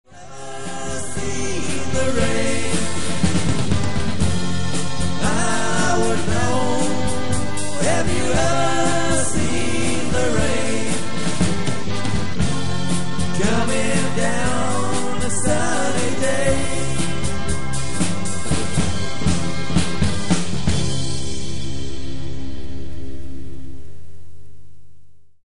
Erste Tonaufnahme der"neuen" Besetzung 1998: